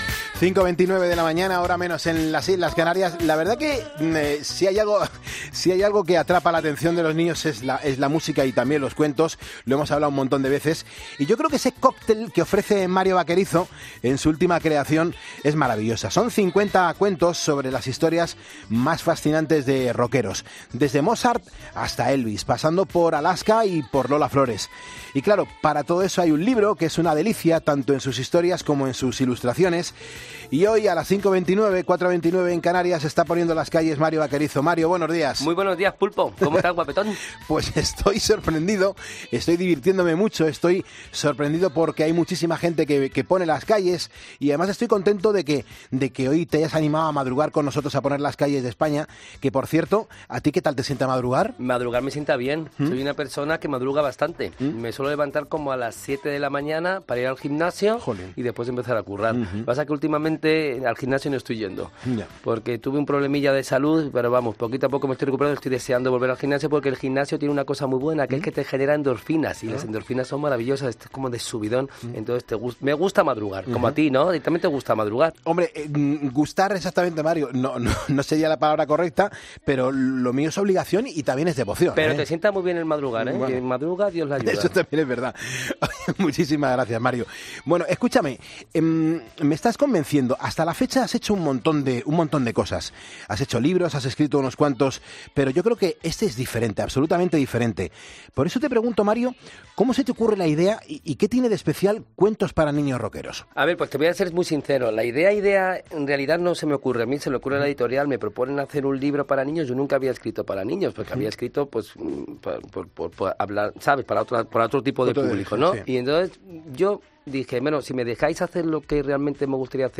Entrevista Mario Vaquerizo 'Poniendo las Calles'